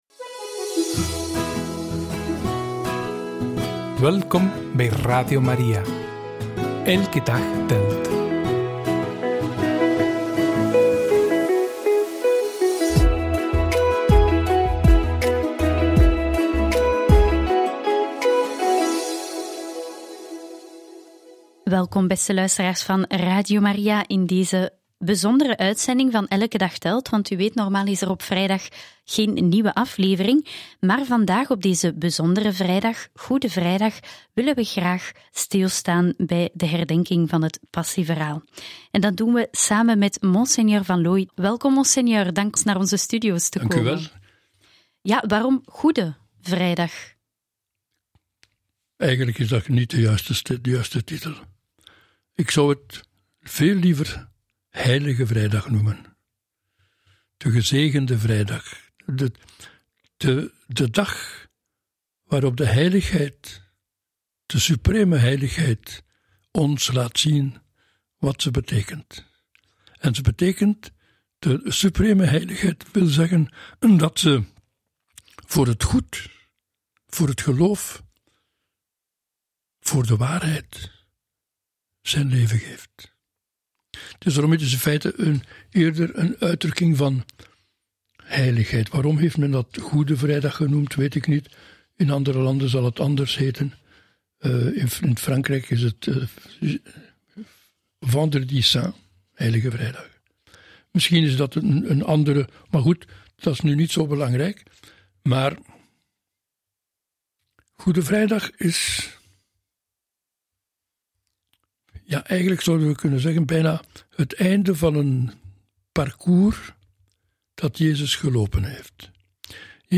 tijdens de Eucharistieviering op Witte Donderdag